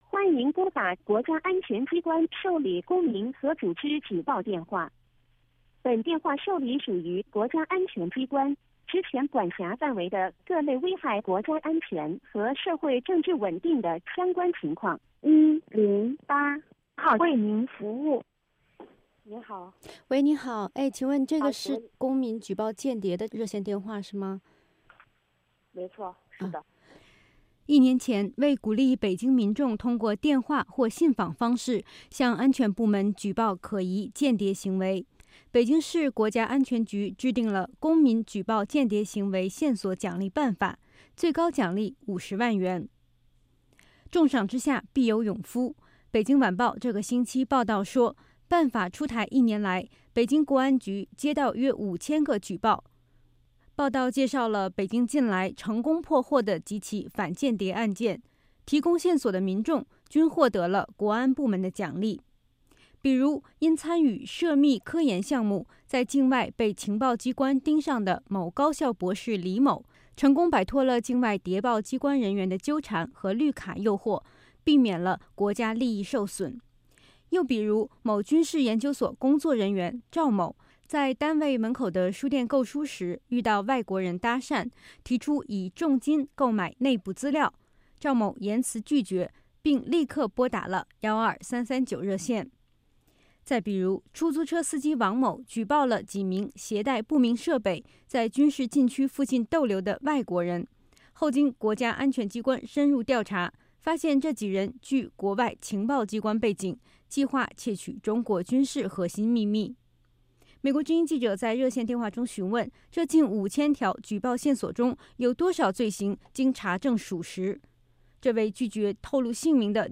一段电话录音后，传来接线员的声音：“您好，108号为您服务。”